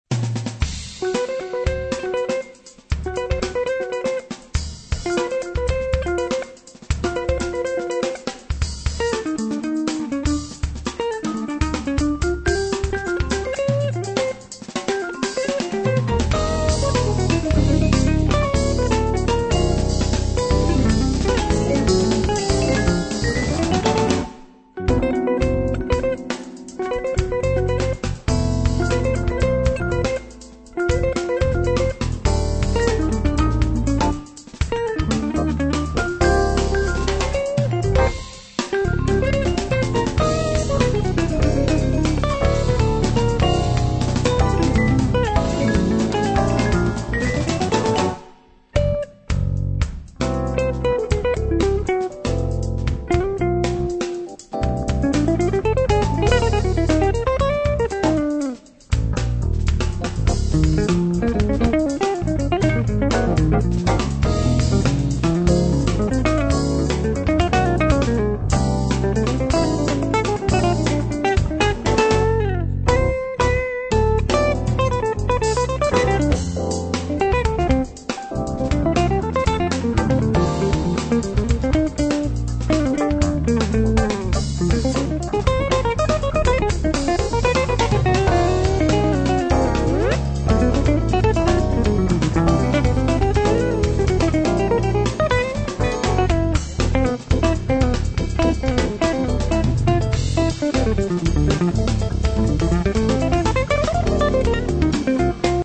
Bas gitarıyla albüm boyunca harikalar yaratıyor.